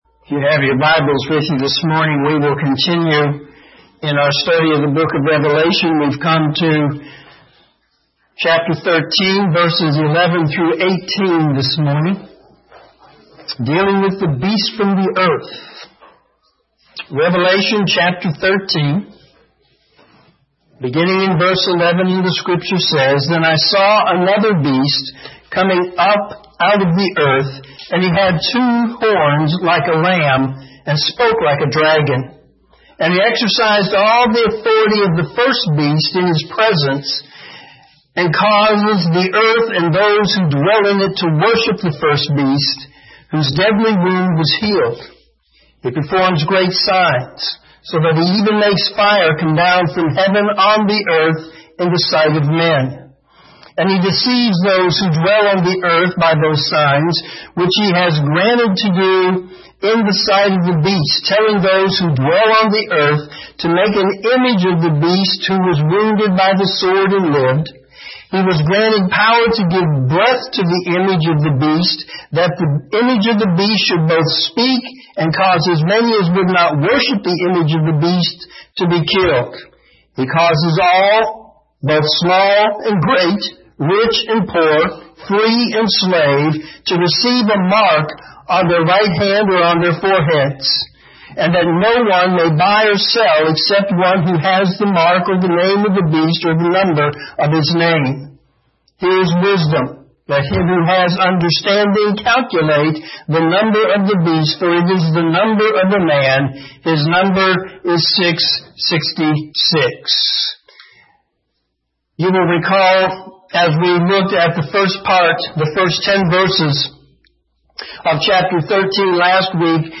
Morning Sermon Revelation 13:11-18
sermon9-3-17.mp3